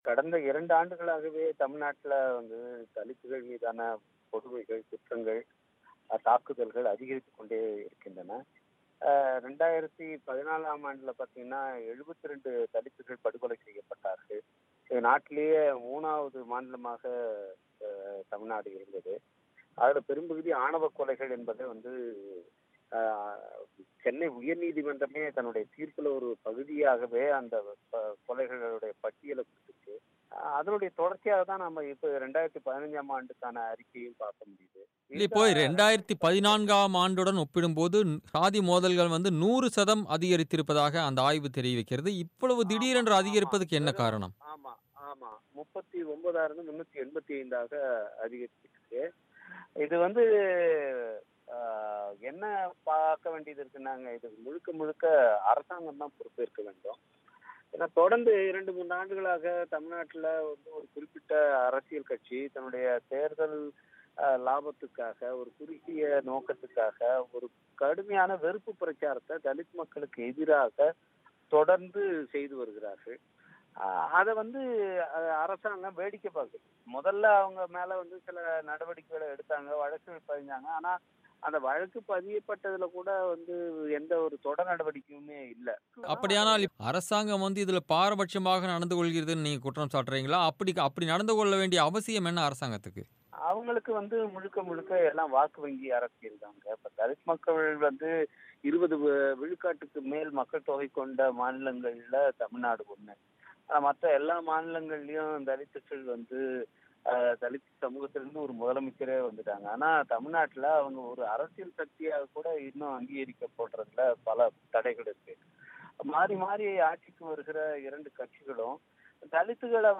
தமிழகத்தில் சாதி மோதல்கள் அதிகரிப்பா? விசிக ரவிக்குமார் பேட்டி